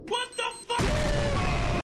Wtf Boom High Quality Efecto de Sonido Descargar
Wtf Boom High Quality Botón de Sonido